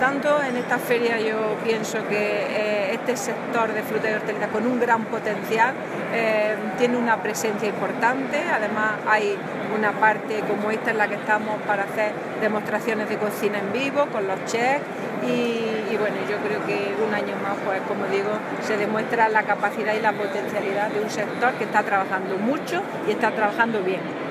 Declaraciones de Carmen Ortiz sobre la presencia andaluza en Fruit Attraction 2016